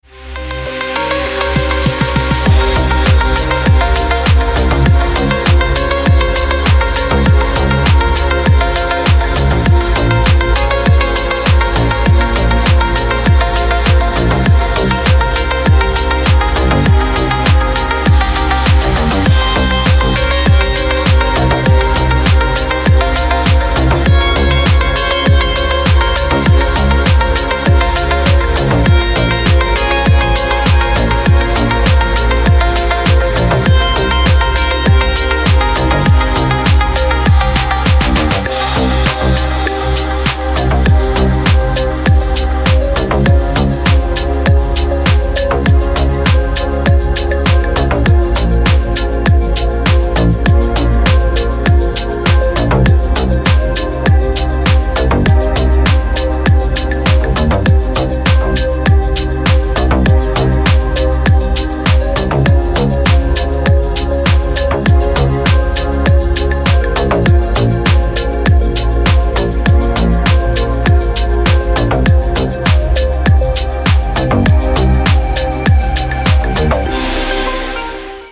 Deep House рингтоны